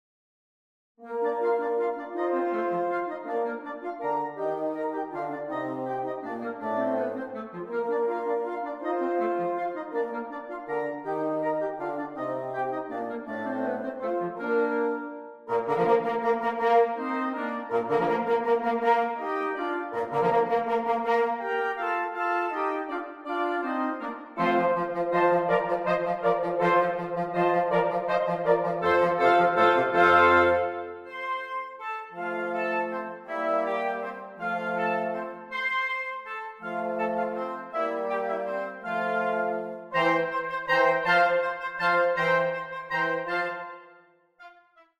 2 oboes, 2 clarinets, 2 horns, 2 bassoons